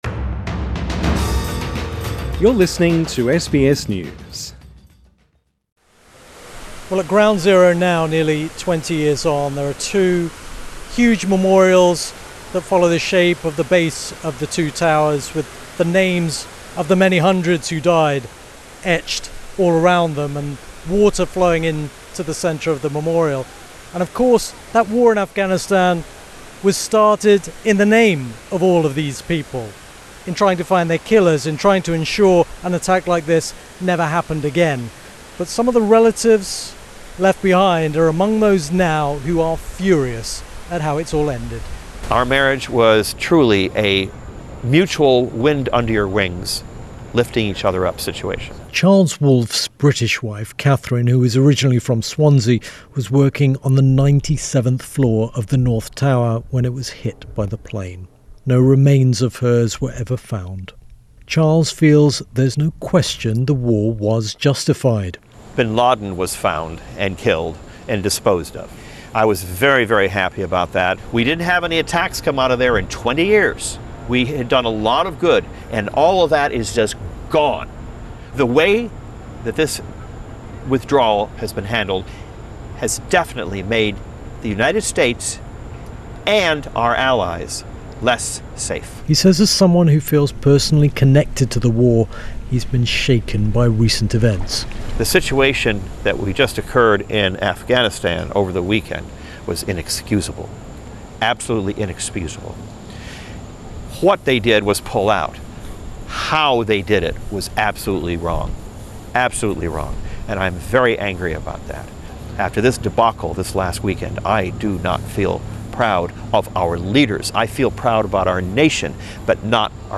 This story was first broadcast on the BBC World Service